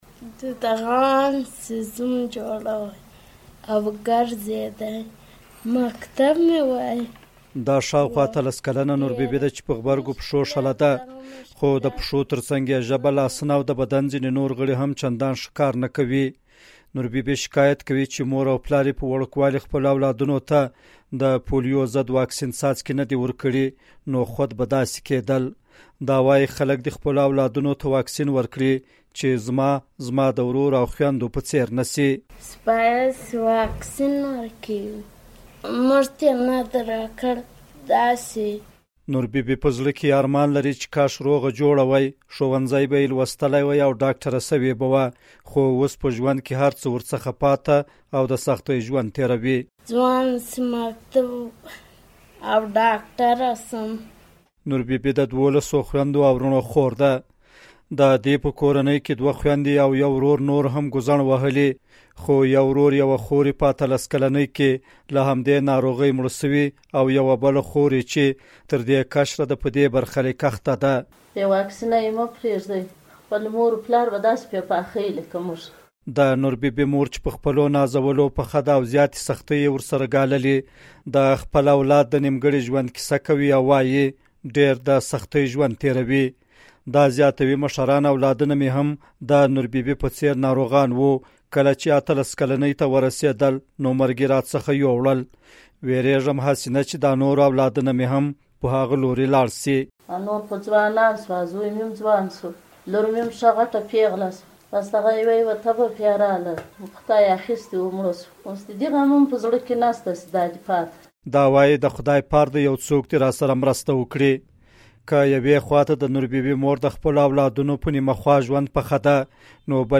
د غزني ولایت راپور